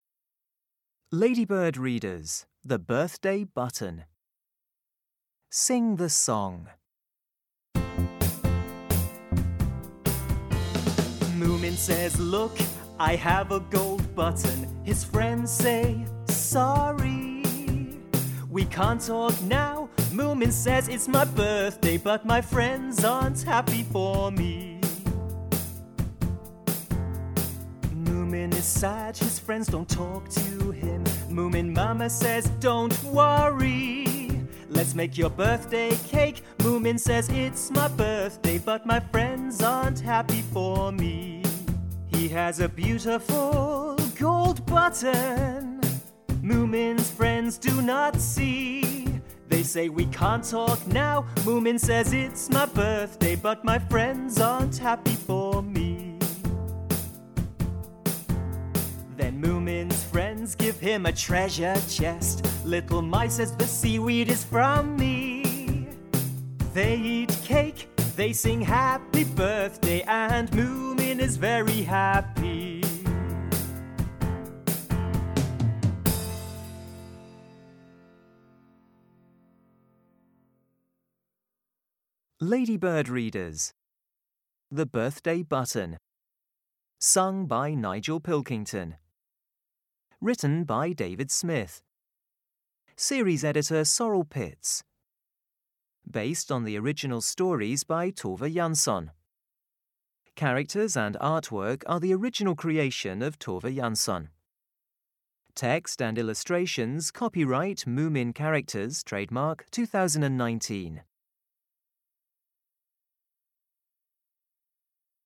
Song -